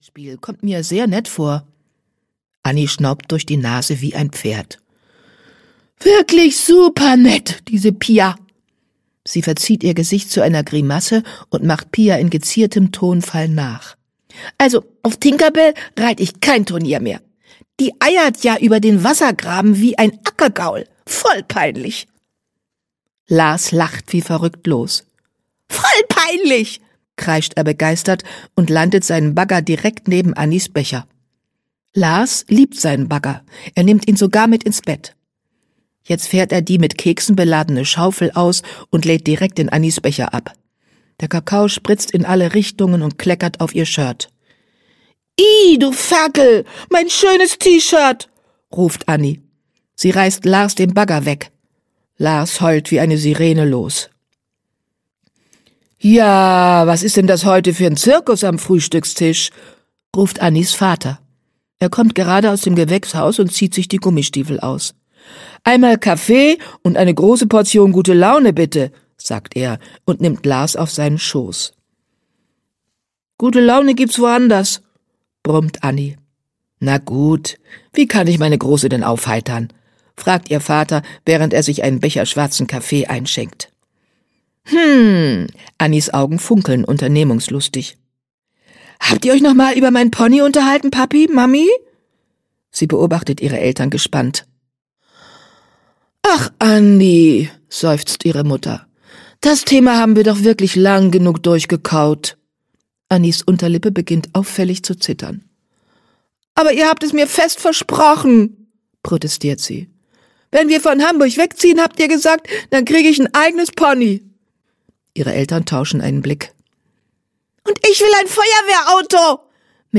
Ponyherz 1: Anni findet ein Pony - Usch Luhn - Hörbuch